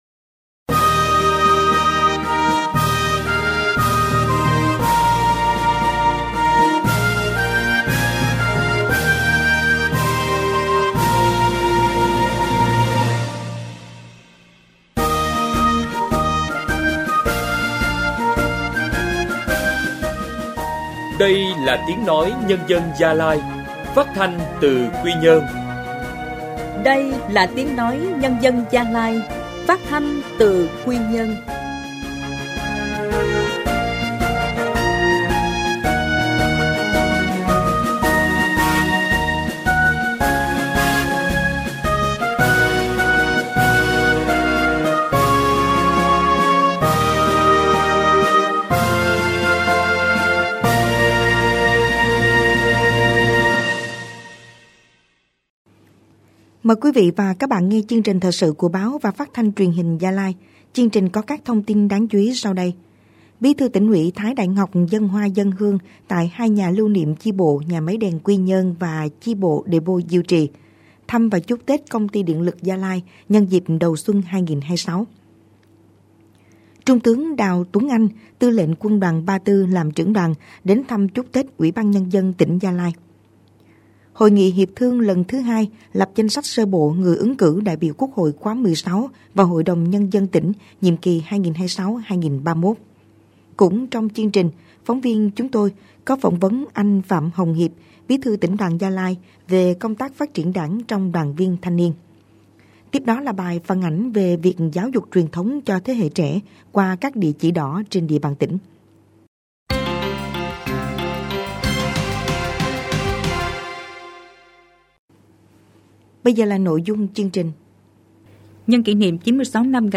Thời sự phát thanh tối